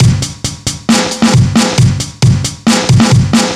Live Break 135.wav